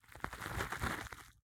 Minecraft Version Minecraft Version 1.21.5 Latest Release | Latest Snapshot 1.21.5 / assets / minecraft / sounds / block / chorus_flower / death1.ogg Compare With Compare With Latest Release | Latest Snapshot